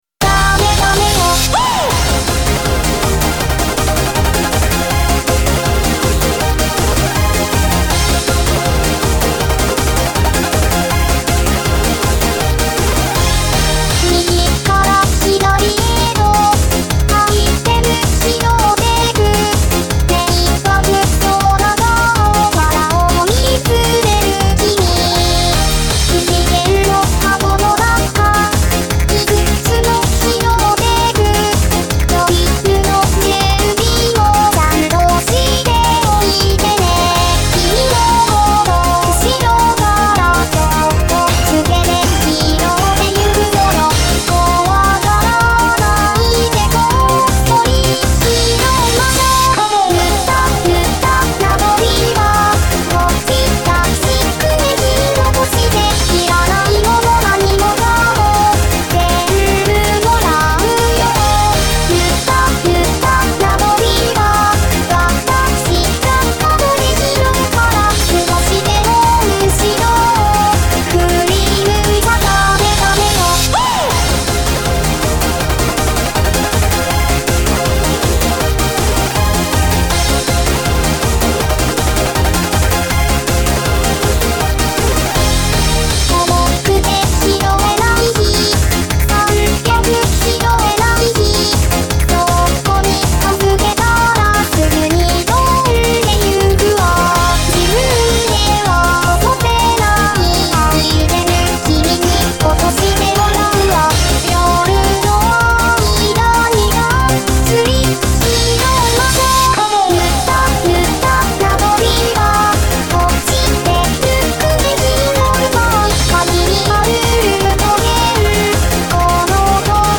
take1よりは、音質とかも良くなっているはずです